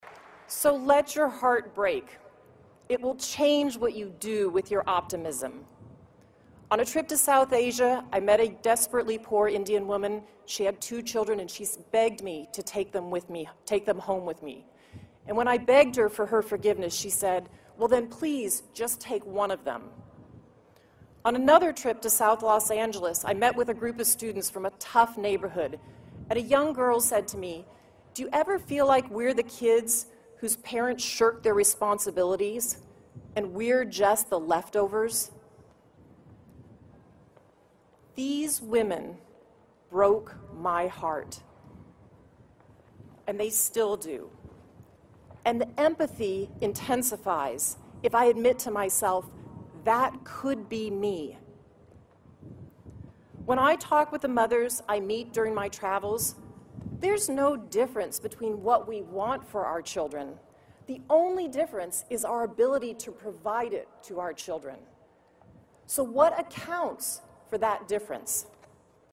公众人物毕业演讲第38期:比尔盖茨夫妇于斯坦福大学(19) 听力文件下载—在线英语听力室